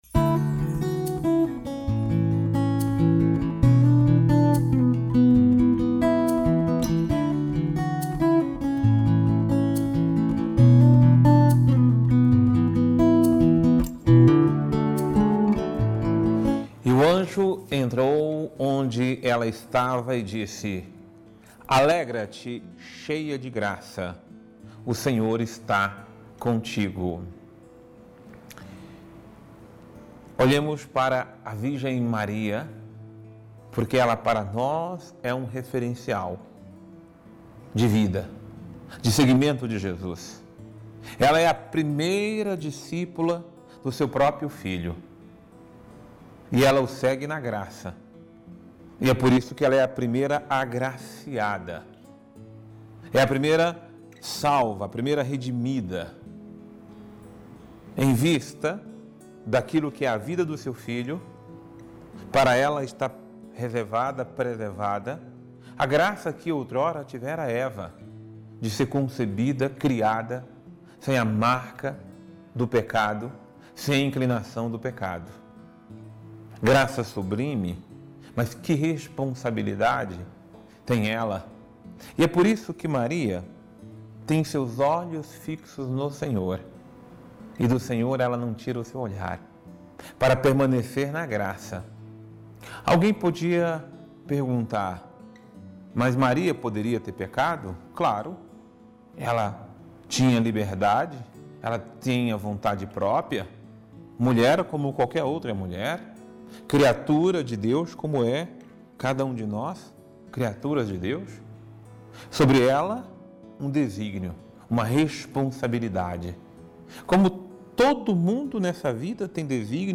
Homilia diária | Maria é nosso referencial de fé